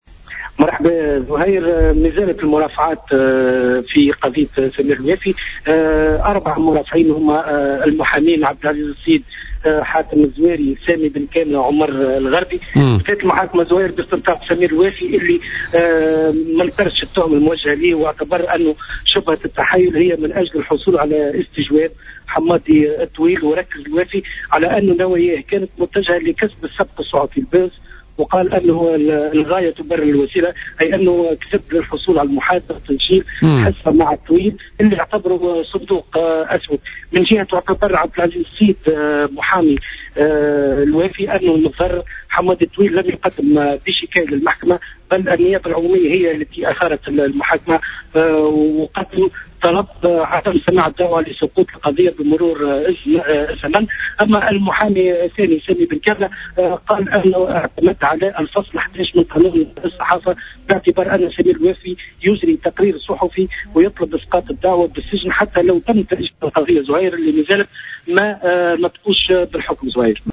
إفادة